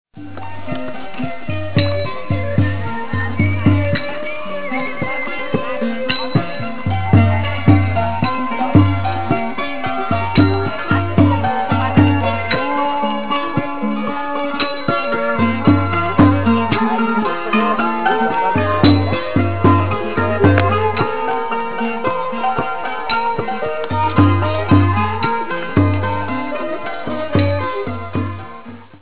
Please click on the picture to hear the musicians performing. I was told it is Cambodian wedding music.